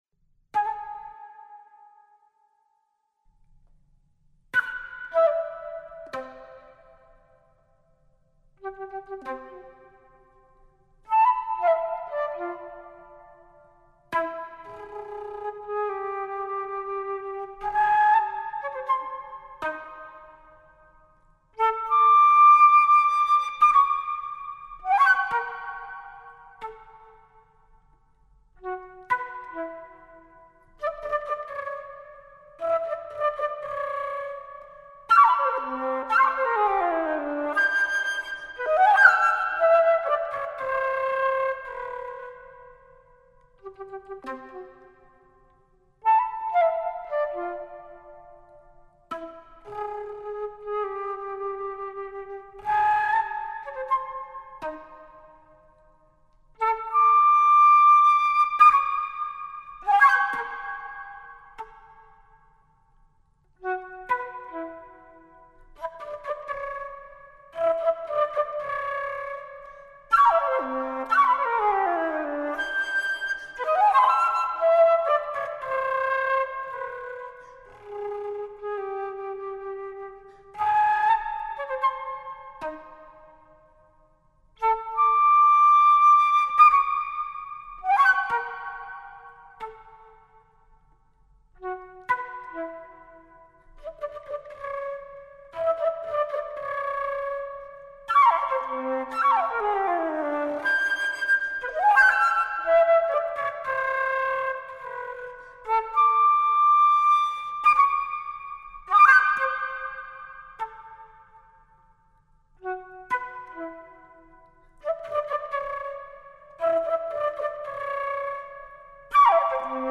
Scored for Solo Flute